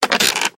Звуки кресла-качалки
Расслабляющий скрип и равномерный ритм покачивания создают атмосферу уюта и спокойствия, идеальную для снятия стресса, медитации или в качестве фона для чтения.
Скрипнуло кресло кто-то поднялся